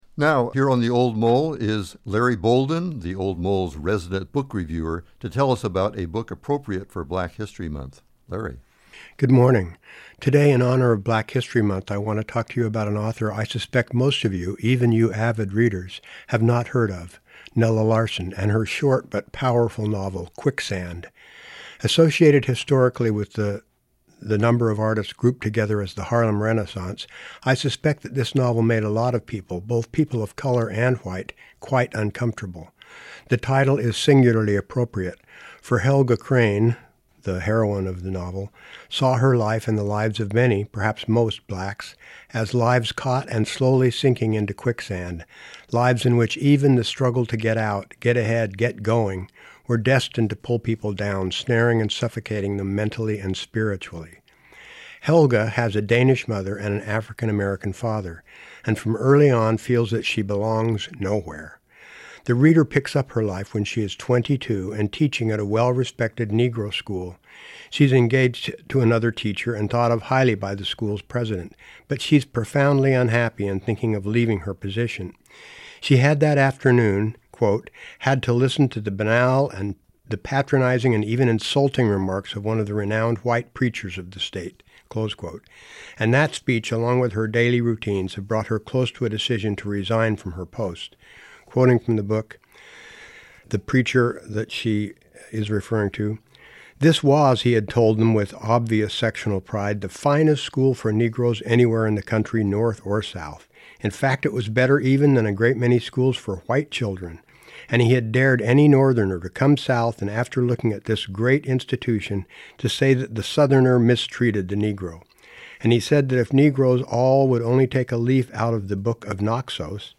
reviews the Harlem Renaissance novel by Nella Larsen